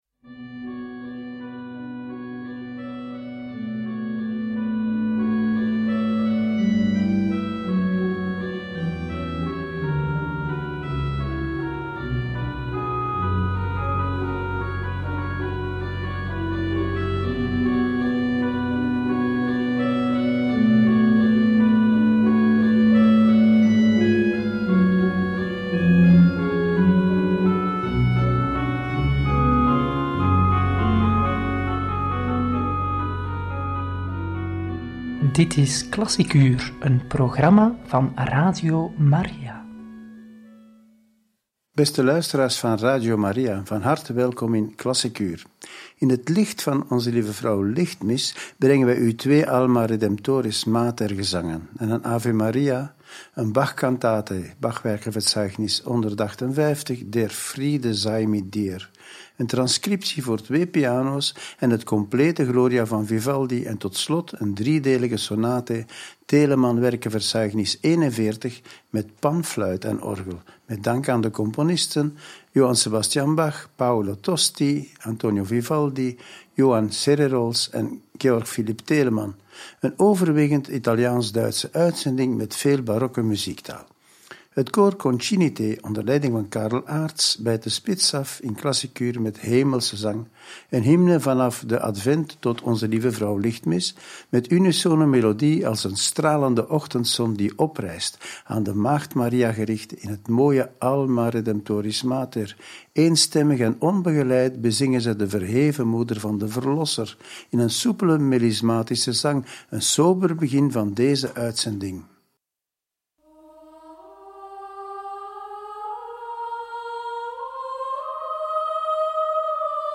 Een Ave Maria, concerto voor piano en strijkkwartet – Radio Maria